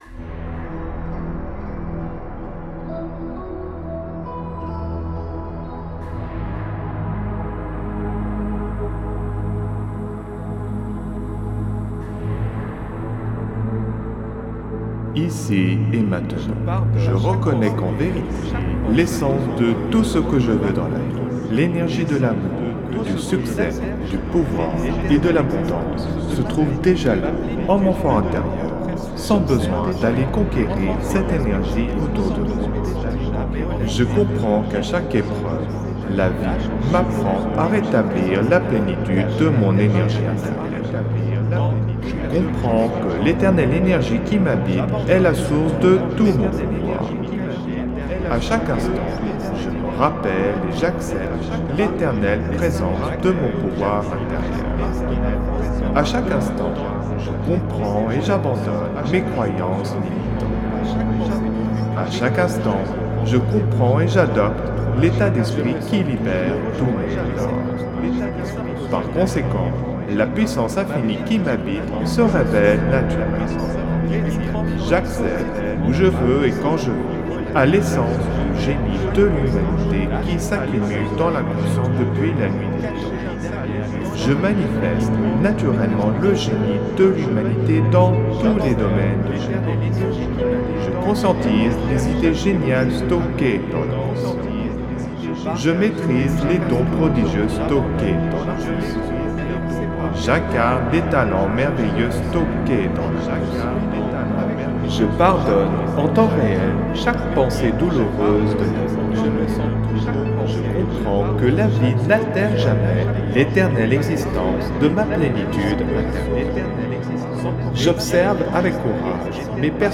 (Version ÉCHO-GUIDÉE)
LA QUINTESSENCE « tout-en-un » du développement personnel concentrée en un seul produit : Méditation, autosuggestion, message subliminal, musicothérapie, son binaural, fréquences sacrées, son isochrone, auto hypnose, introspection, programmation neurolinguistique, philosophie, spiritualité, musique subliminale et psychologie.
Alliage ingénieux de sons et fréquences curatives, très bénéfiques pour le cerveau.